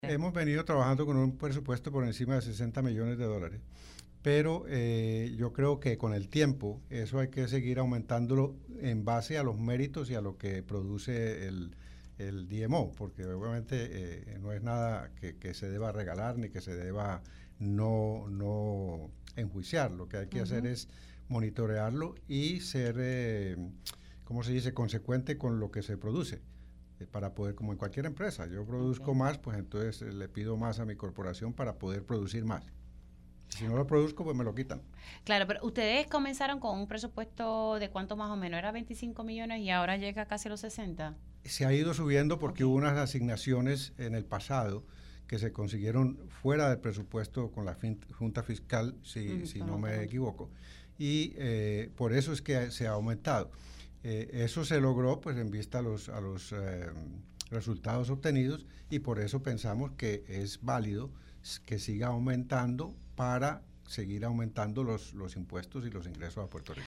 Si no lo produzco, pues me lo quitan“, indicó el hotelero en entrevista para Pega’os en la Mañana.